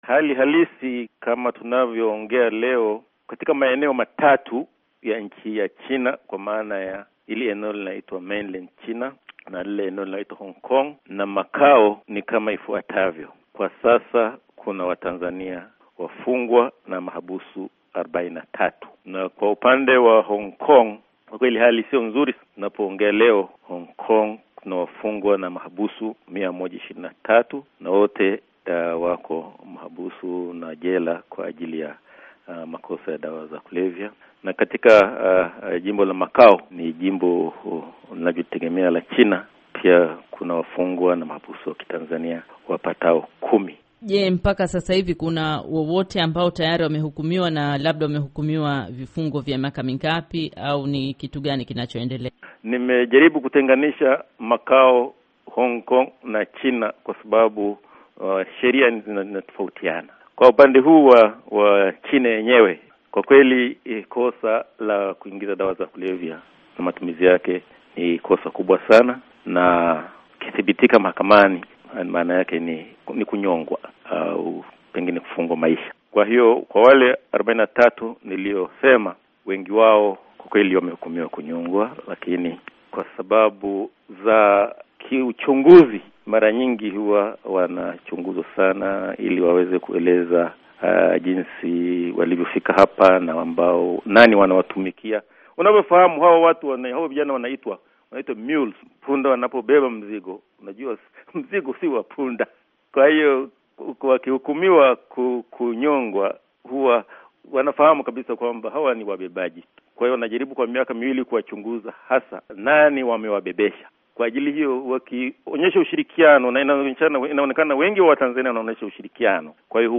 Mahojiano na Balozi wa Tanzania Nchini China